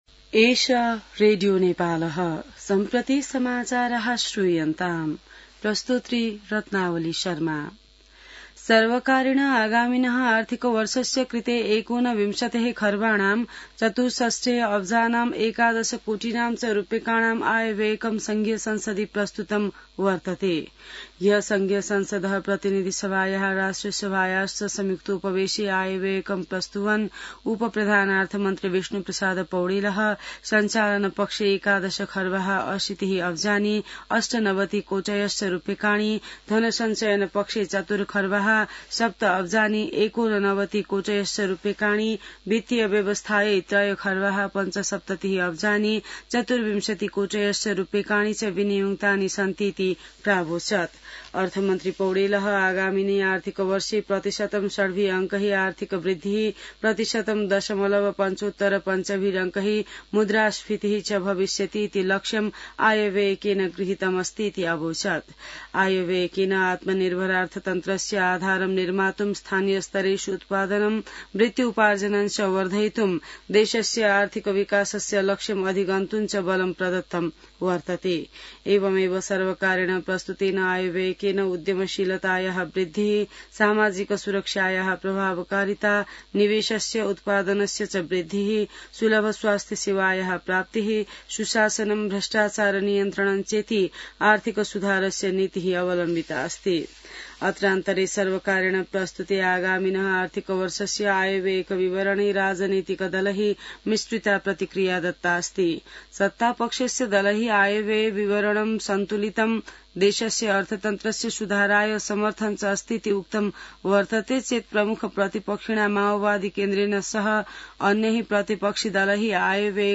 संस्कृत समाचार : १६ जेठ , २०८२